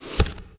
ui_interface_48.wav